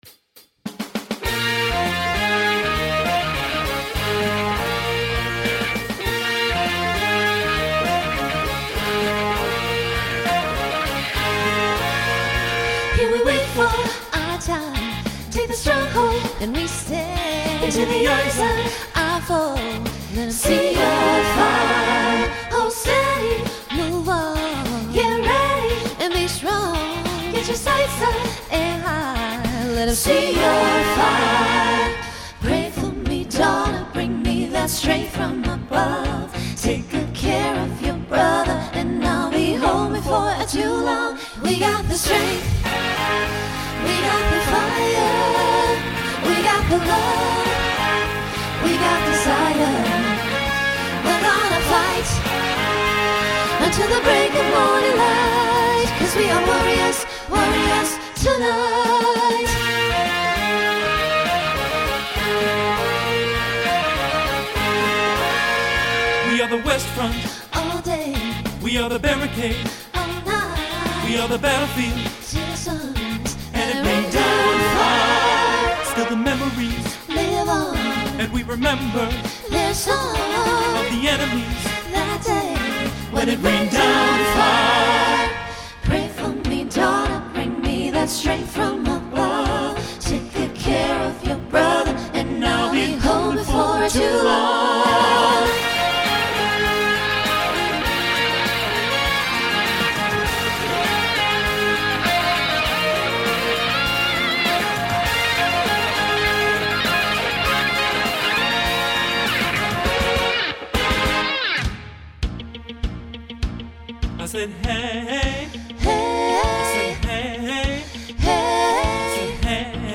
Voicing SATB Instrumental combo Genre Rock
Mid-tempo , Opener